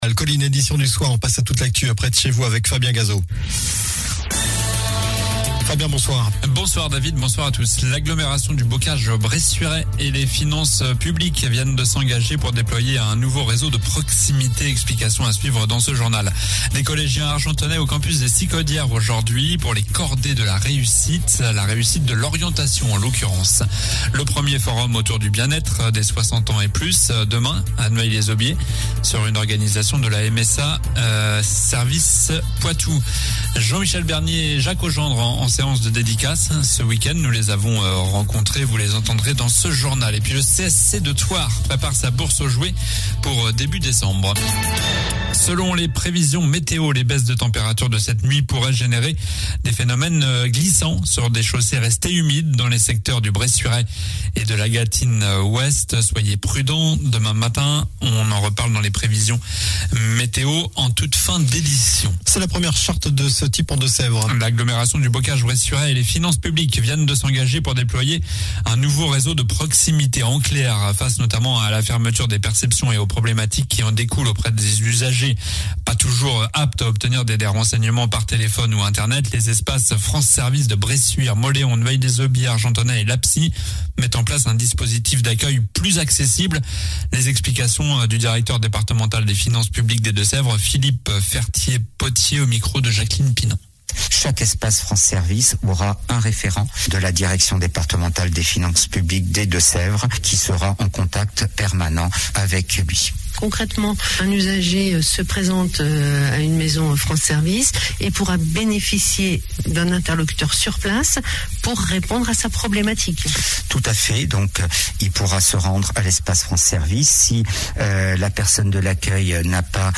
JOURNAL DU VENDREDI 39 OCTOBRE ( SOIR )